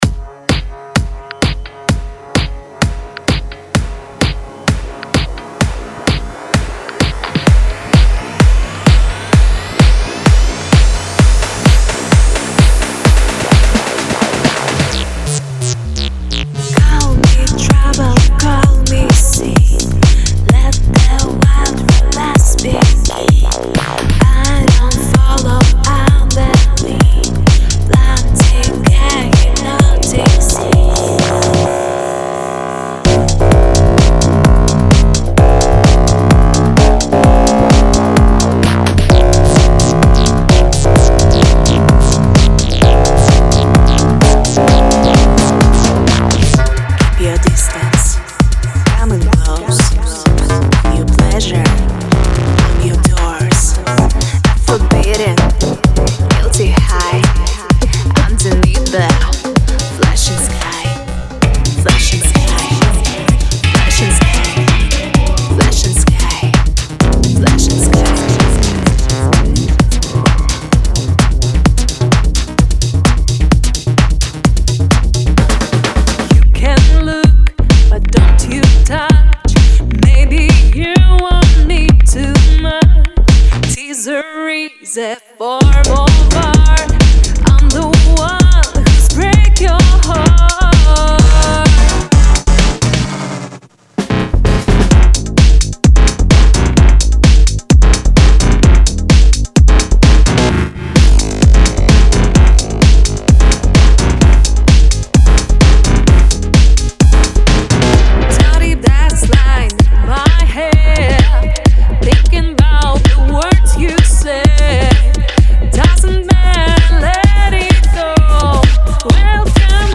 デモサウンドはコチラ↓
Genre:Tech House
60 Female Vocal Loops